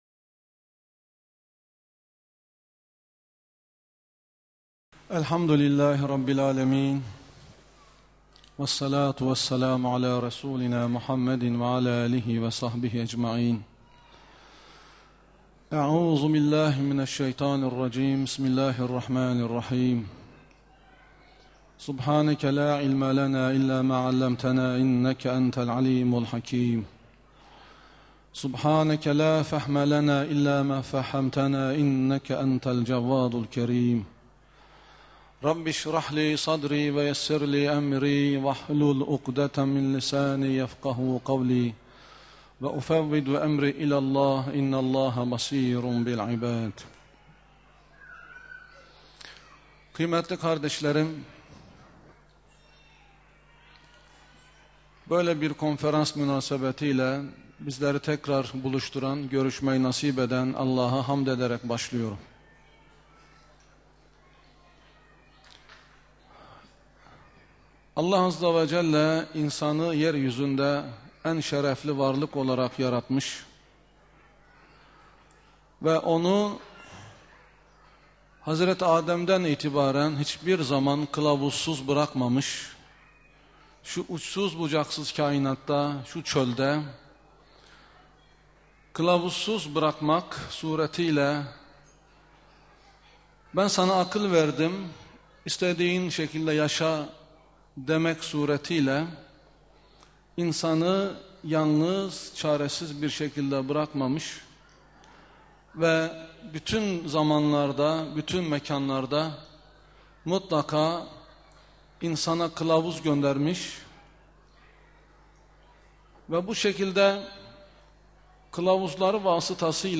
2013-osmaniye-kuranin-hedefledigi-insan-konferans.mp3